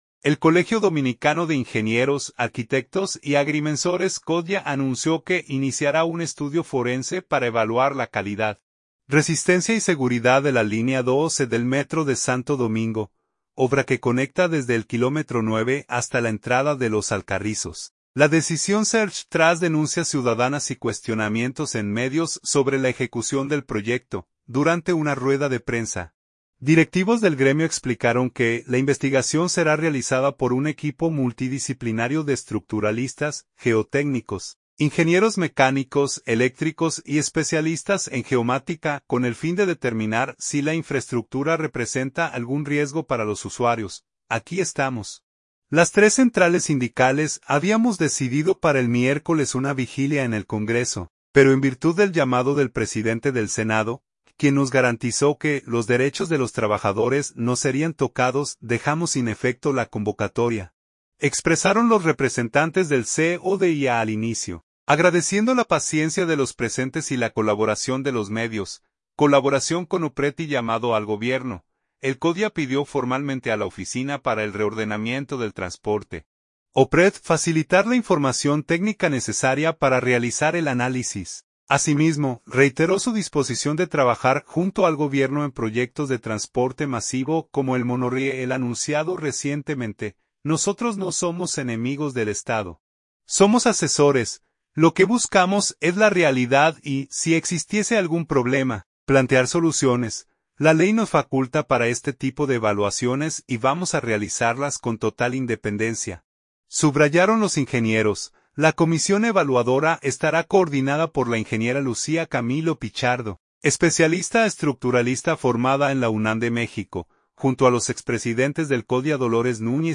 Durante una rueda de prensa, directivos del gremio explicaron que la investigación será realizada por un equipo multidisciplinario de estructuralistas, geotécnicos, ingenieros mecánicos, eléctricos y especialistas en geomática, con el fin de determinar si la infraestructura representa algún riesgo para los usuarios.